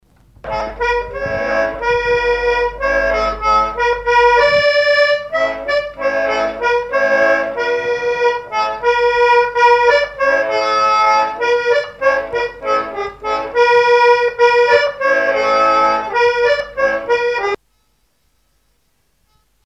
Usage d'après l'informateur circonstance : fiançaille, noce ;
Genre strophique
accordéoniste
Pièce musicale inédite